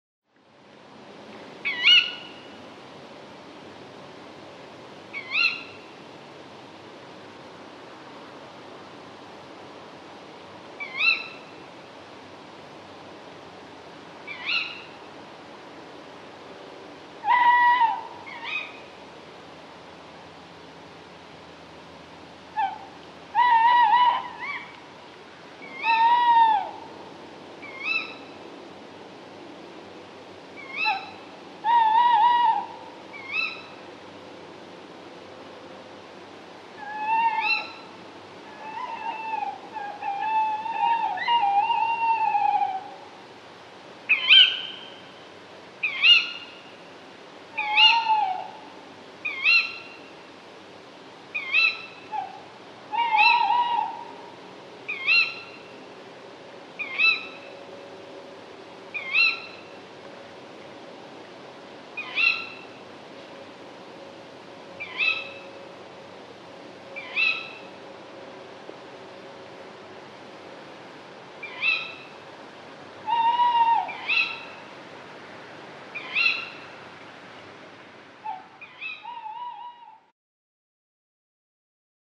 Tawny owls
Recorded at Gibside, this short audio clip starts with a female owl calling, before the male joins in.
The female owl does the tuwit call, and the male answers with the tuwoo.